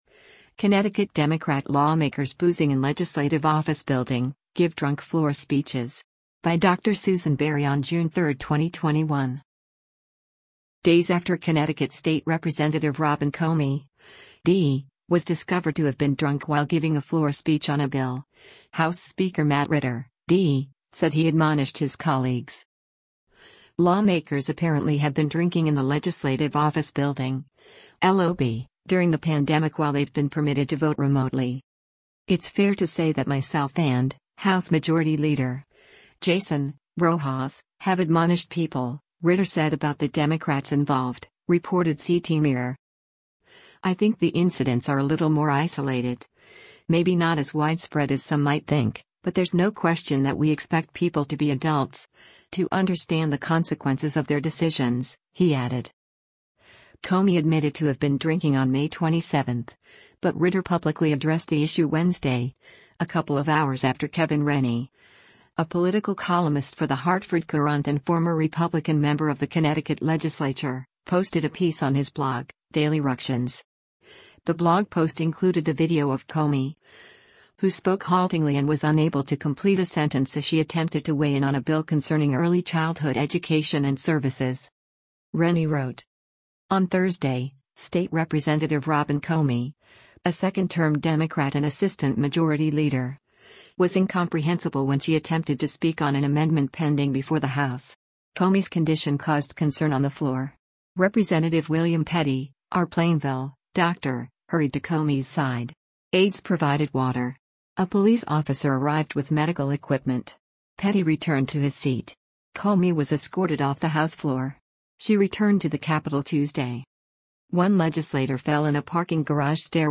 CT State Rep Robin Comey Gives Drunk Floor Speech
The blog post included the video of Comey, who spoke haltingly and was unable to complete a sentence as she attempted to weigh in on a bill concerning Early Childhood Education and Services.
On Thursday, State Representative Robin Comey, a second term Democrat and Assistant Majority Leader, was incomprehensible when she attempted to speak on an amendment pending before the House.